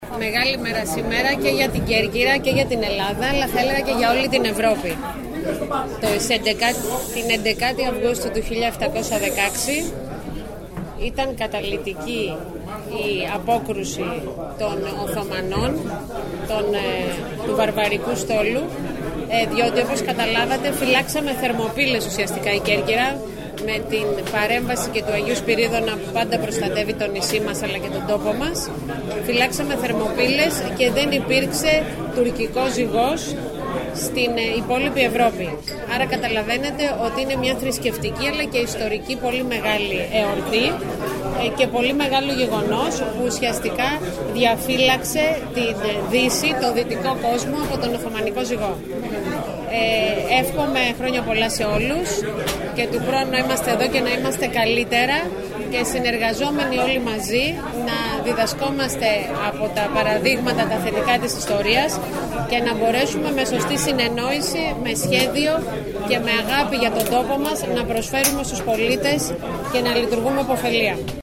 Κέρκυρα: Δηλώσεις πολιτικών και αυτοδιοικητικών (audio)